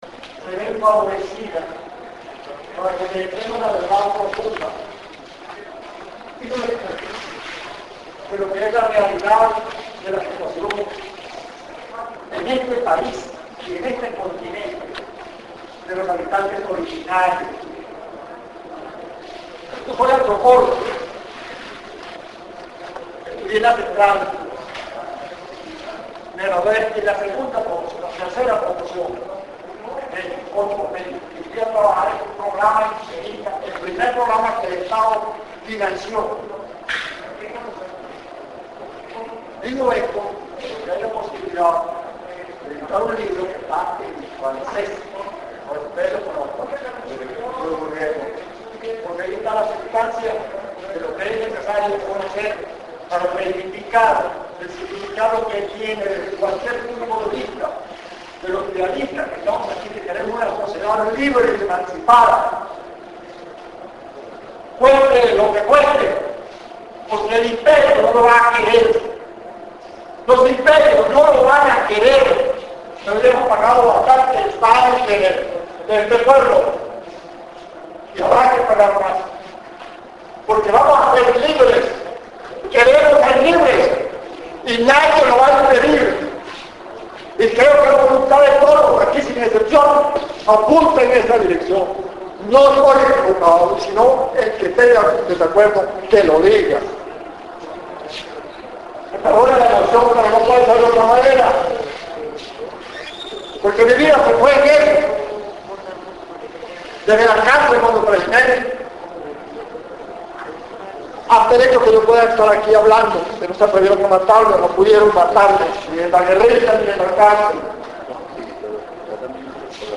En la plural plenaria del 2do Seminario Internacional de Integración Latinoamericana DESDE ABAJO